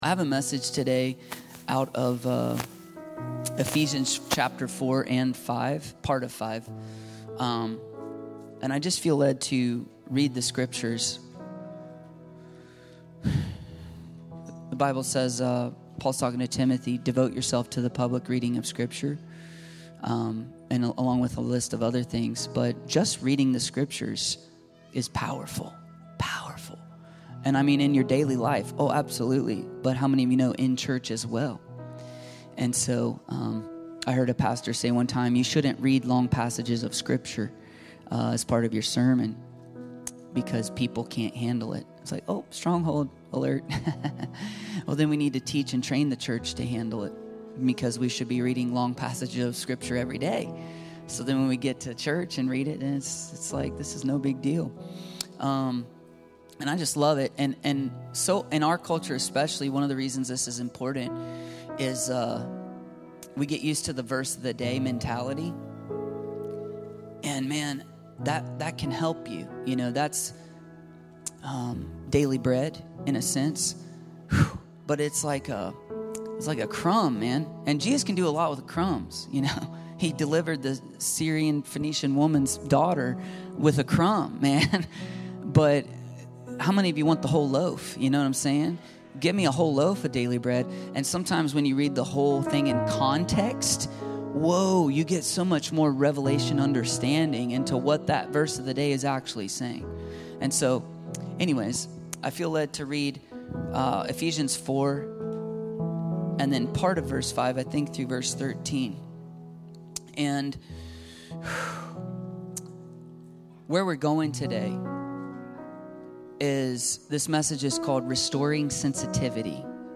Restoring Sensitivity - Restoring Sensitivity ~ Free People Church: AUDIO Sermons Podcast